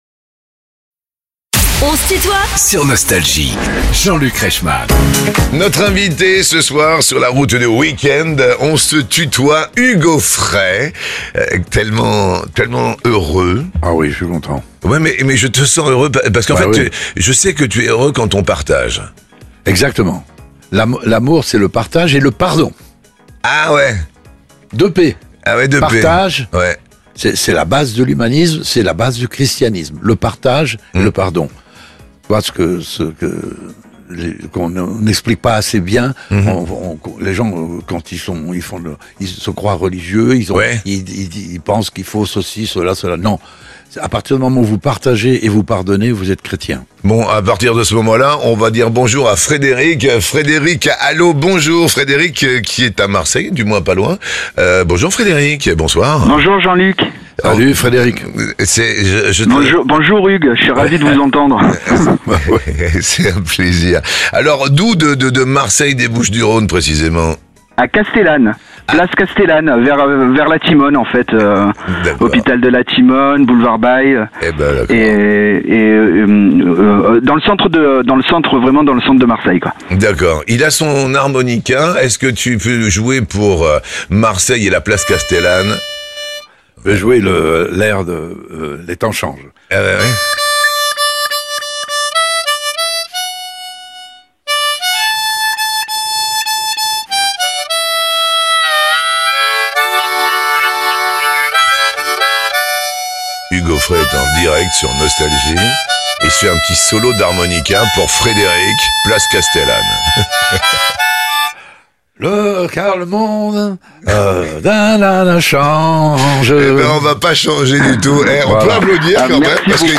Hugues Aufray évoque sa relation avec Johnny Hallyday ~ Les interviews Podcast
L’interprète de "Santiano" et du "Petit âne gris" se confie sur sa rencontre avec le taulier pour qui il a adapté le tube "Le pénitencier". Hugues Aufray est l'invité de "On se tutoie ?..." avec Jean-Luc Reichmann